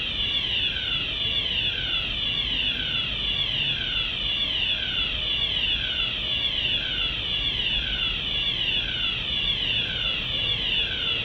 Feueralarm.ogg